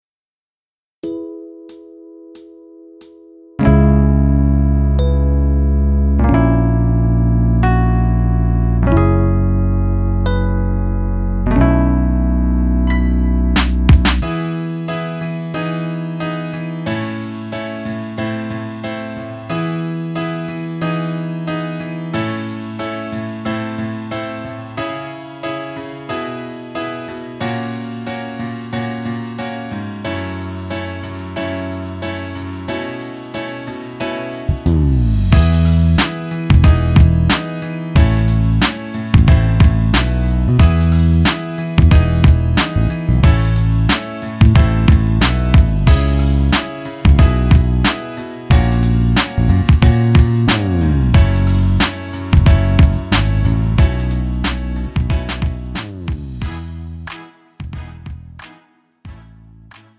음정 원키 3:17
장르 가요 구분 Pro MR